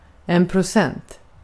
Ääntäminen
Ääntäminen Tuntematon aksentti: IPA: /prʊˈsɛnt/ Haettu sana löytyi näillä lähdekielillä: ruotsi Käännös Ääninäyte Substantiivit 1. percent US 2. per cent Artikkeli: en .